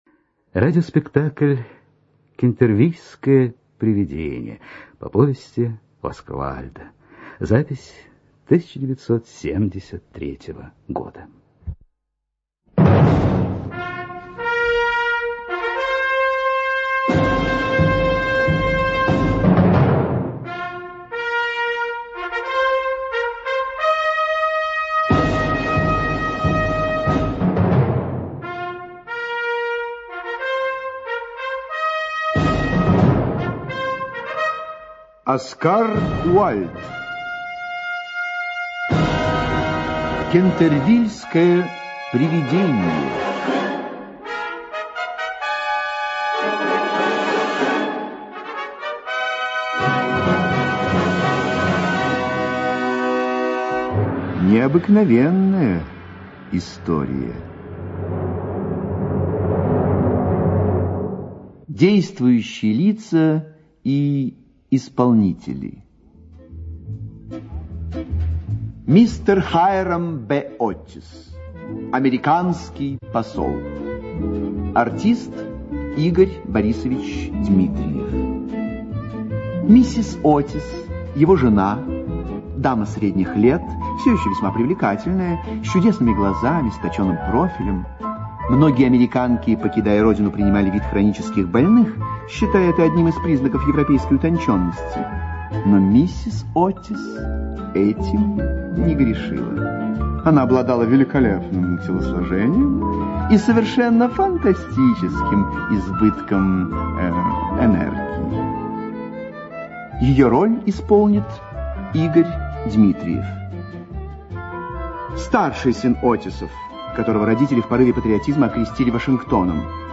Аудиокнига Кентервильское привидение
Качество озвучивания весьма высокое.